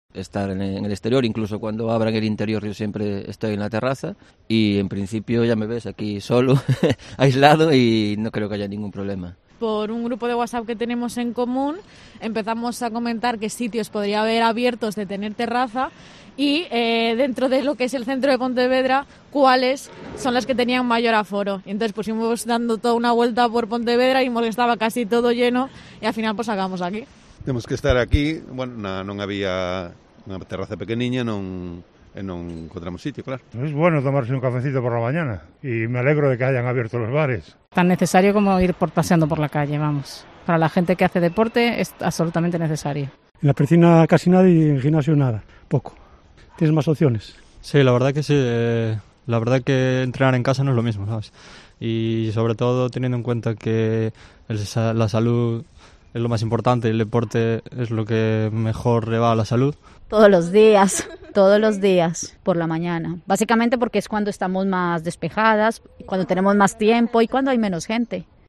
Declaraciones de vecinos de Pontevedra sobre las medidas de la desescalada